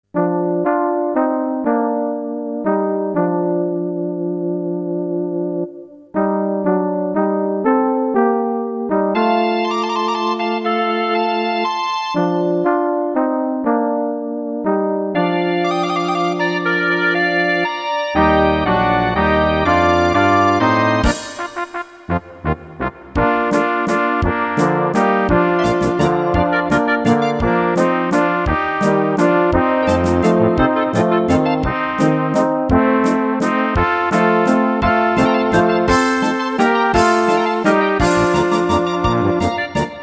Rubrika: Národní, lidové, dechovka
- polka